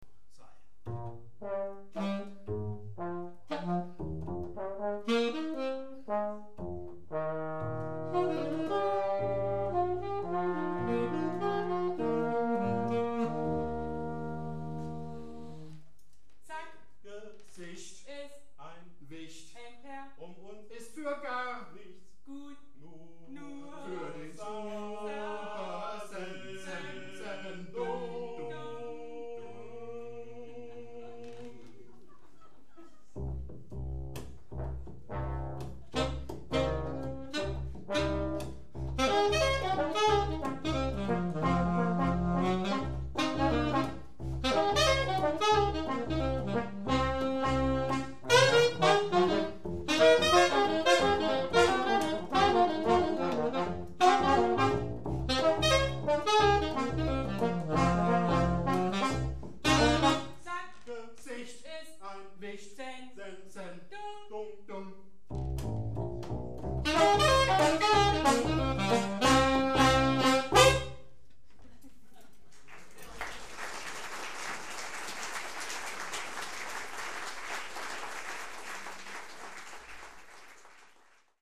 Konzert 12.
· Genre (Stil): Jazz